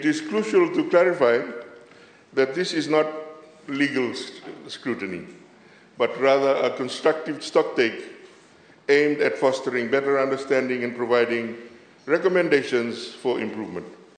While speaking in Parliament yesterday, Kamikamica stressed how trade has opened up new horizons, generating fresh opportunities, and promoting innovation within the country.
Trade Minister, Manoa Kamikamica.